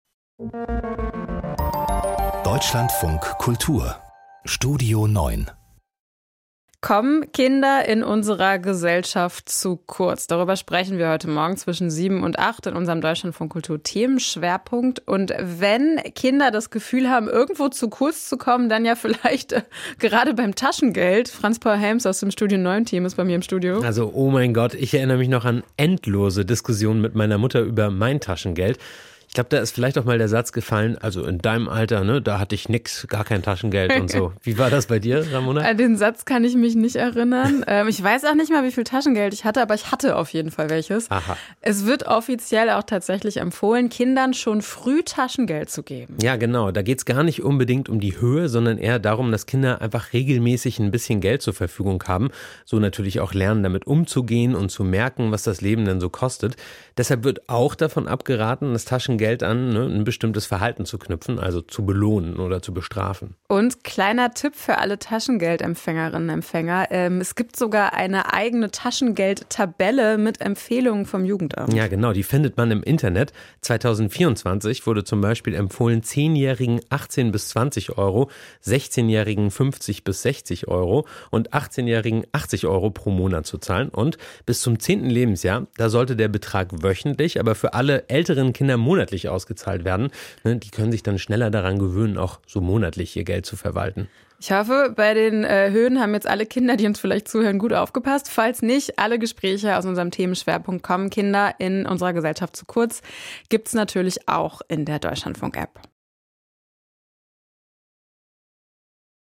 Ein Kommentar.